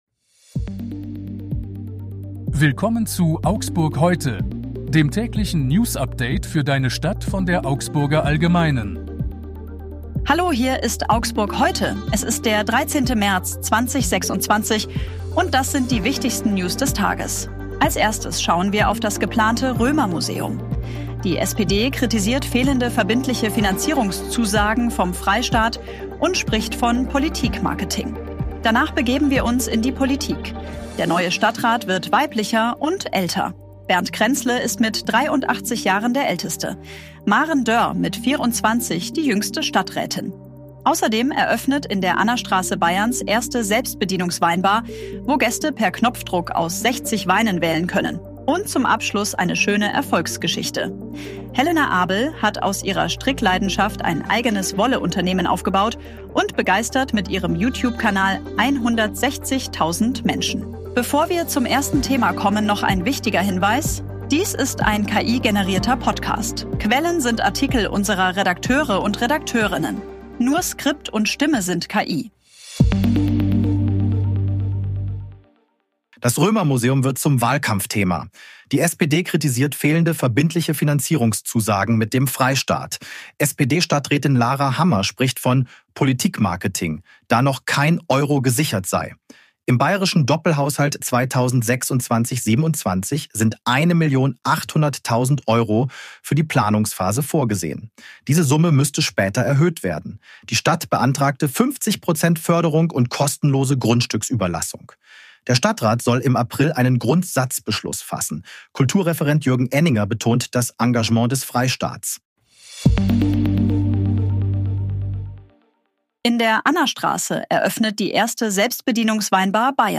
Nur Skript und Stimme sind KI.